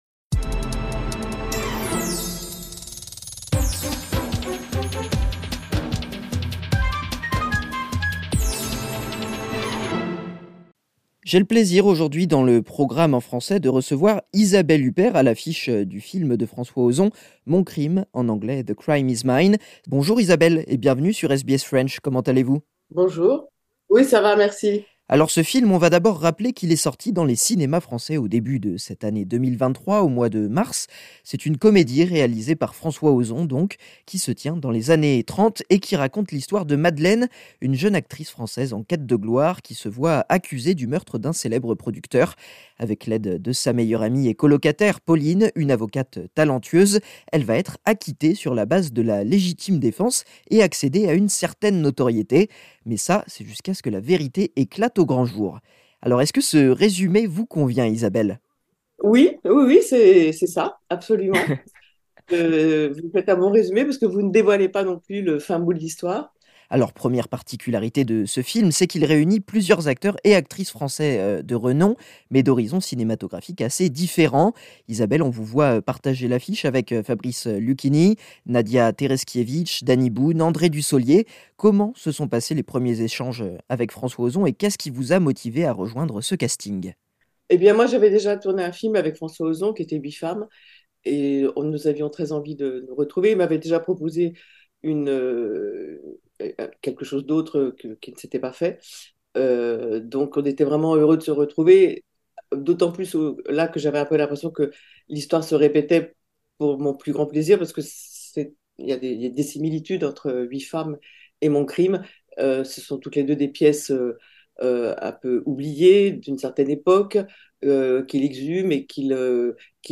Une comédie signée François Ozon tirée d'une pièce de théâtre des années 1930. Isabelle Huppert revient sur son rôle aux côtés de Nadia Tereszkiewicz, Rebecca Marder, Fabrice Luchini ou encore Dany Boon, et se confie sur son rapport à l'Australie.